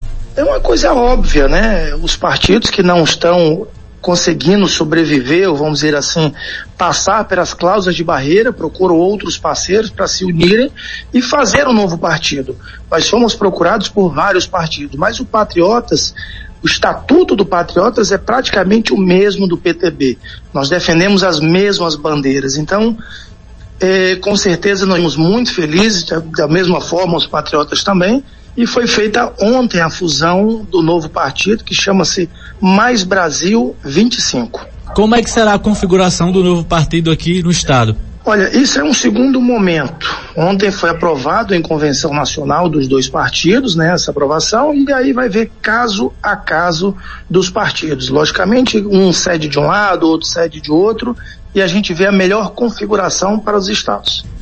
As declarações repercutiram em entrevista ao programa Arapuan Verdade, nesta quinta-feira (27).